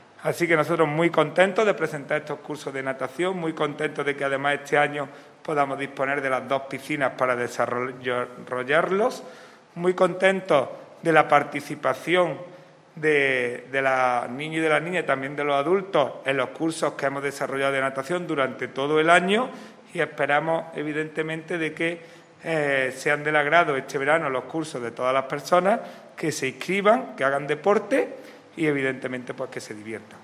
El teniente de alcalde delegado de Deportes, Juan Rosas, ha presentado esta mañana en rueda de prensa otra nueva iniciativa que vuelve a retomarse con la llegada del verano y que sigue con la pretensión de tratar de impulsar a que la población, sea de la edad que sea, haga deporte como hábito saludable.
Cortes de voz